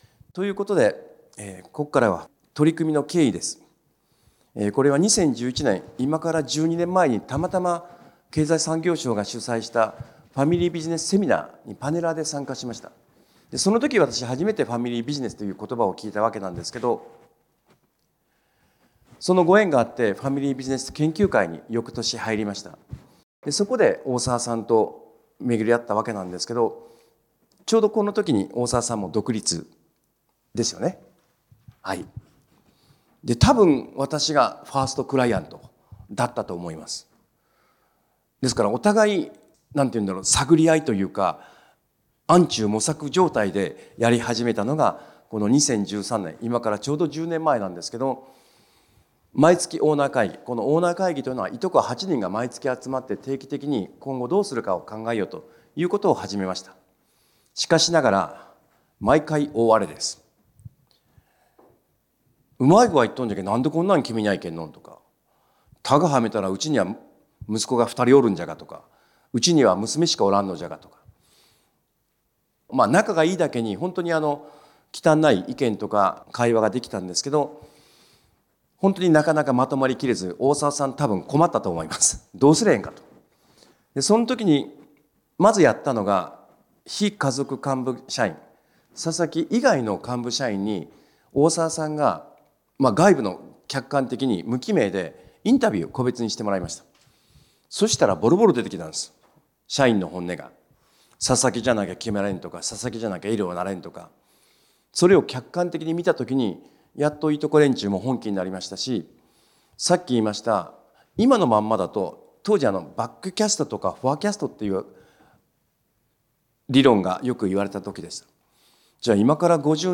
「ファミリ―ビジネス永続発展の仕組み」講話のサンプル音声をお聴きいただけます。